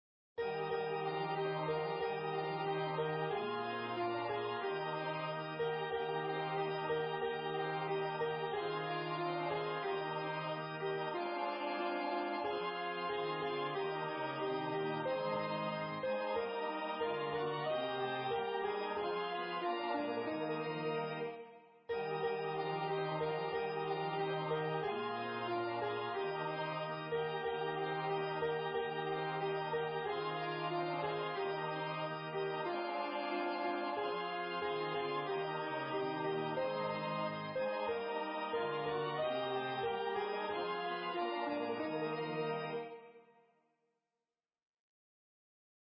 • MP3 Practice Files: Soprano:
Number of voices: 2vv   Voicing: SA
Genre: SacredUnknown
Instruments: Organ